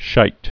(shīt) Chiefly British Vulgar Slang